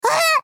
combobreak.ogg